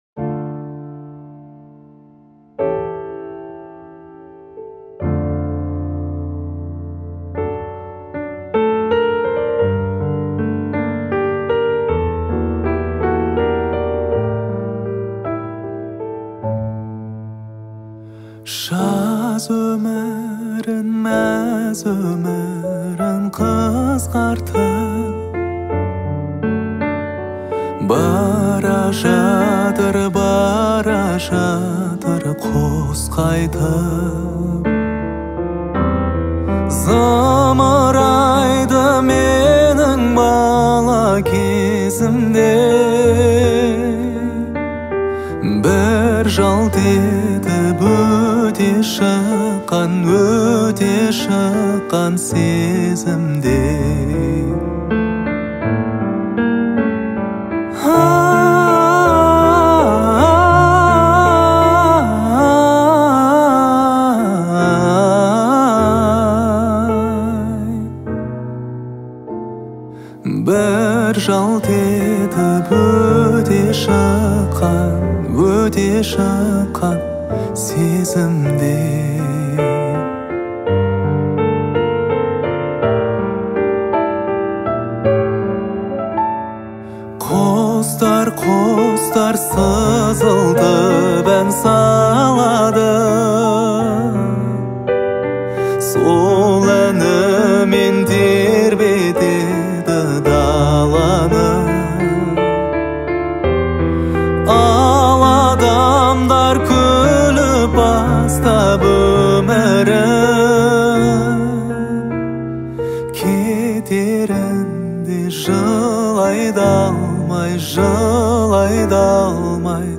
Казахские песни / Казахские песни 2020